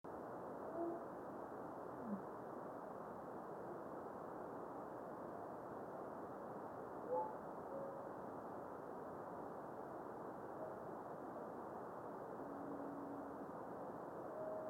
Weak radio reflection but evident.